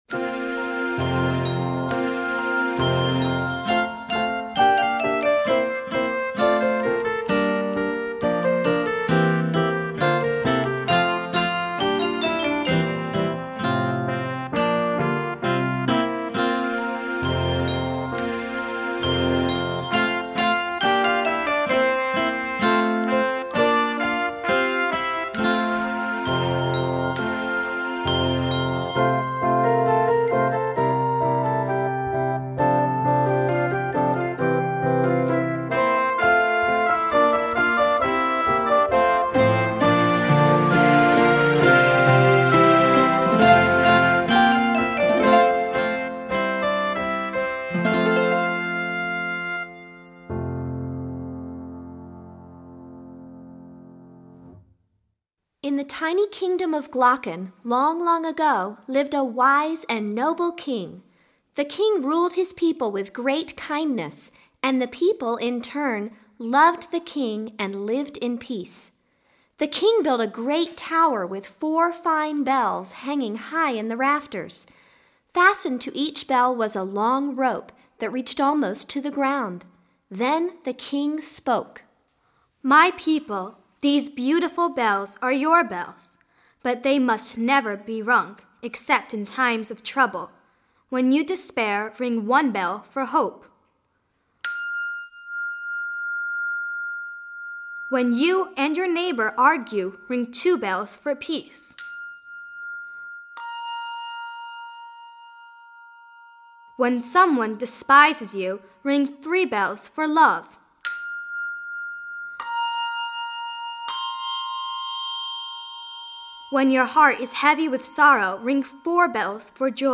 Voicing: Conductor / Piano